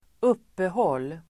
Uttal: [²'up:ehål:]